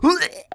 sounds_vomit_01.ogg